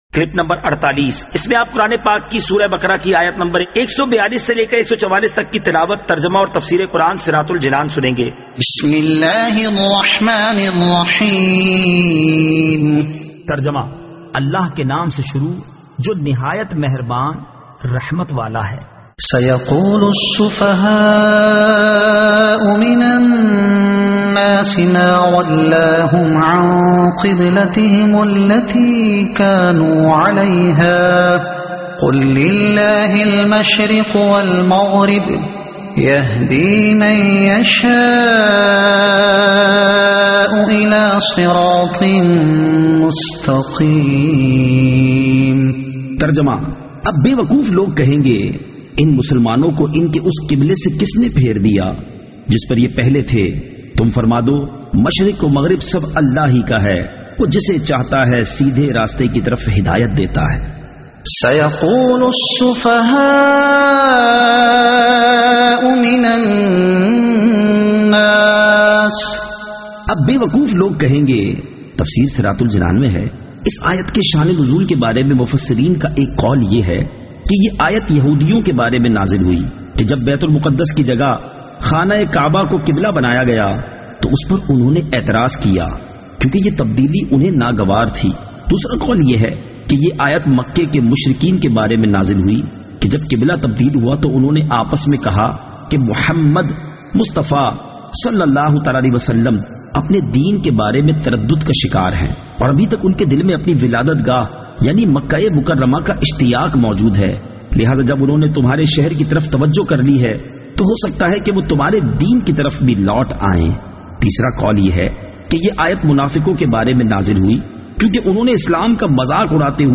Surah Al-Baqara Ayat 142 To 144 Tilawat , Tarjuma , Tafseer